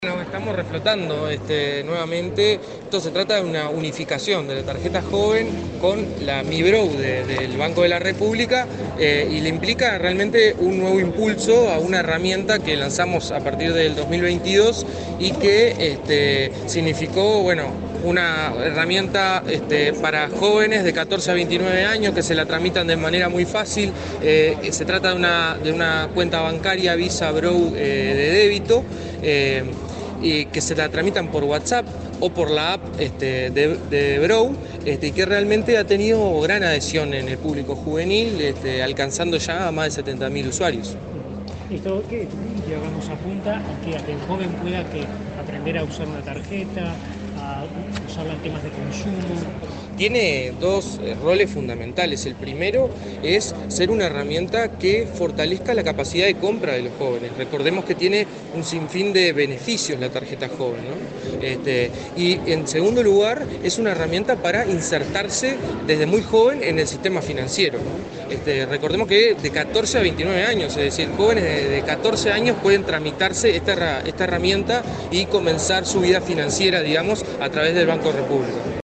Declaraciones del director del INJU, Aparicio Saravia
Declaraciones del director del INJU, Aparicio Saravia 26/07/2024 Compartir Facebook X Copiar enlace WhatsApp LinkedIn El director del Instituto Nacional de la Juventud (INJU), Aparicio Saravia; el titular del Banco de la República, Salvador Ferrer, y el ministro de Desarrollo Social, Alejandro Sciarra, participaron en el lanzamiento de Mi BROU, tarjeta joven. Luego, Saravia dialogó con la prensa, sobre el alcance del nuevo servicio.